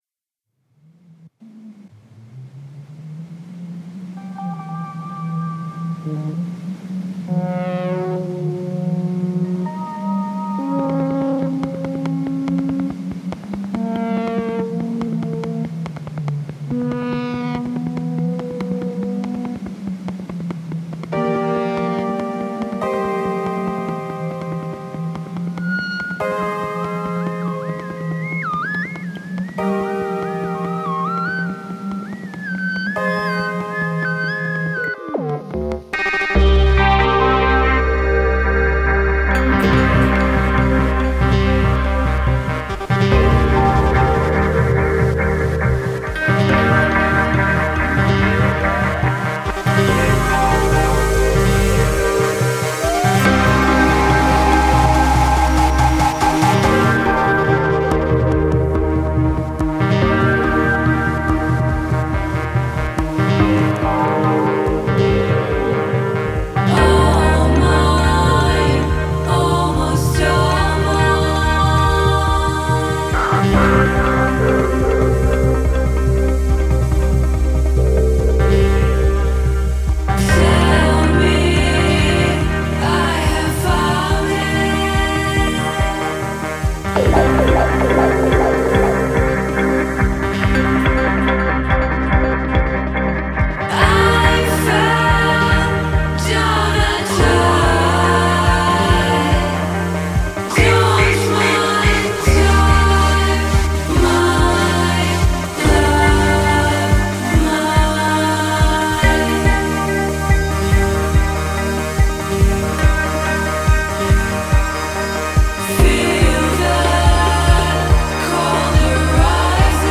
Every third Wednesday of the month I will be playing some of my old favorites and new discoveries music wise. Additionally I’ll be interviewing various visual artists, musicians, writers, cartoonists, animators, film makers, doctors, teachers, the guy at the 7-11, anyone who wants to talk about what they’re up to and what they love (or don’t) about this little stretch of life we’ve been afforded.